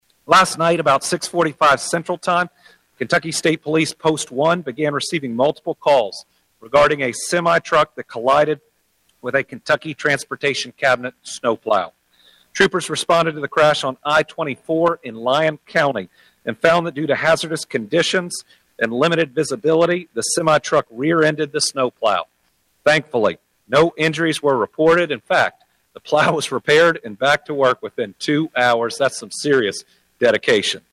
During a Wednesday morning “Team Kentucky” update from Frankfort, Governor Andy Beshear noted Kentucky State Police has received more than 12,500 calls for service in the last 24 hours.